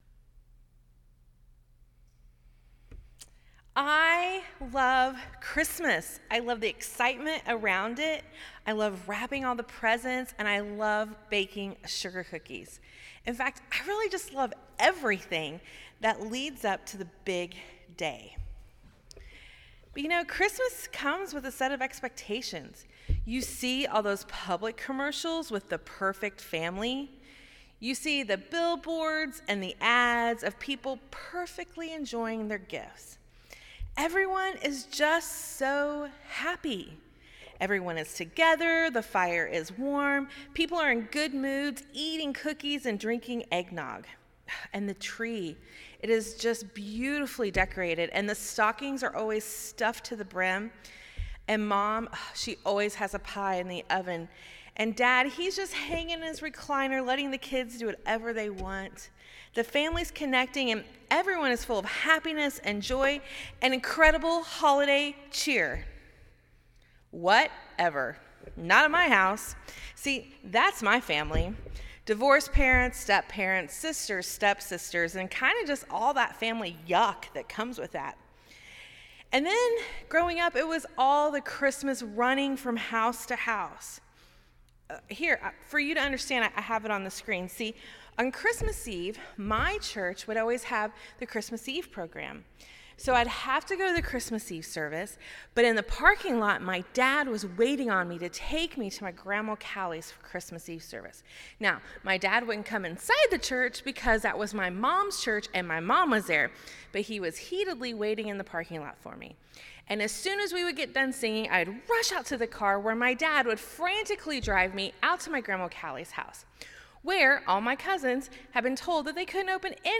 Christmas Eve 2015 Sermon